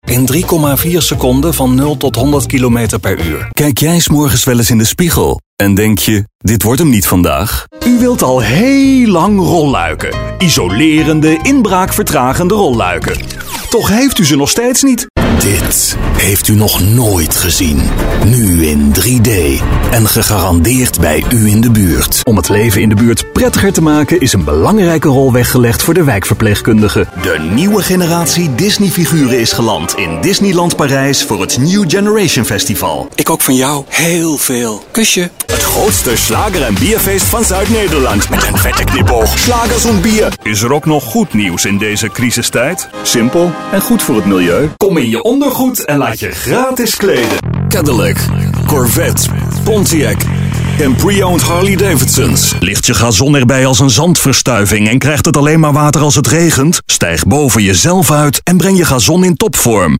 mit eigenem Studio
Sprechprobe: Werbung (Muttersprache):
all round native dutch male voice over talent with own studio